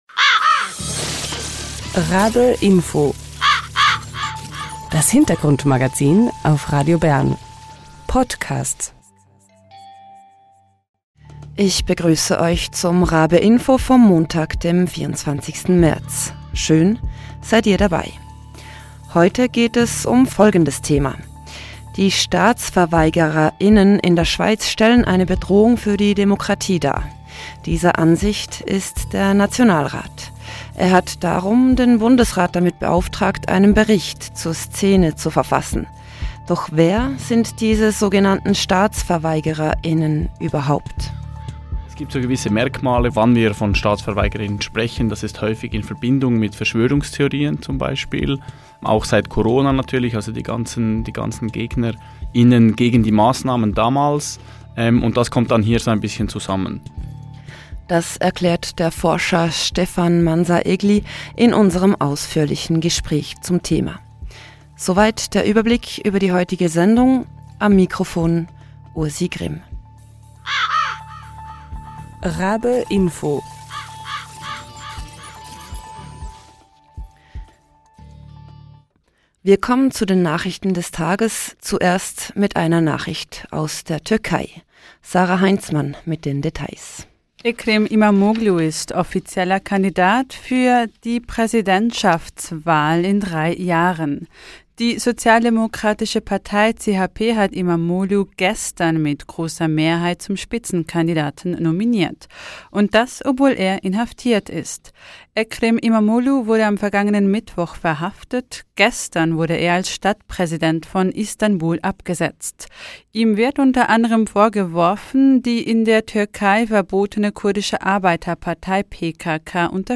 Doch was sind diese sogenannten Staatsverweigerer*innen überhaupt? Ein Gespräch mit...